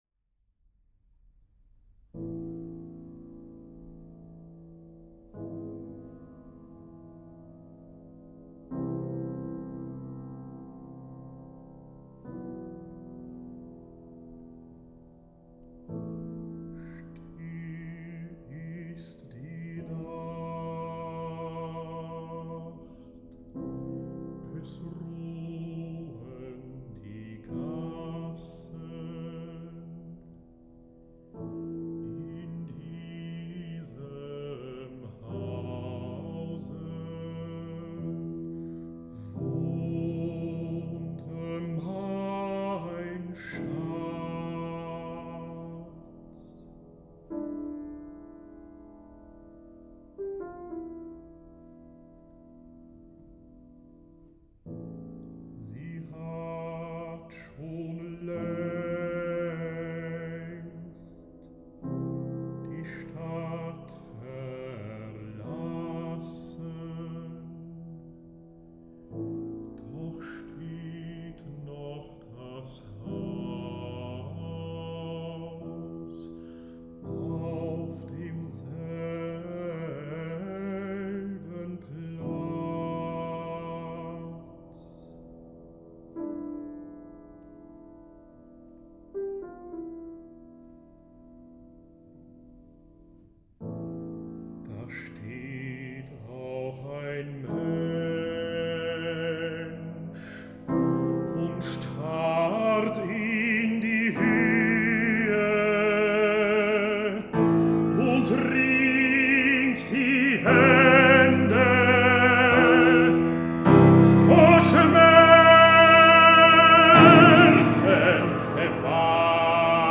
Bariton